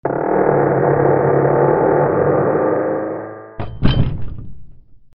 / K｜フォーリー(開閉) / K05 ｜ドア(扉)
城・開閉式・城門・大きな木の扉を閉まる 01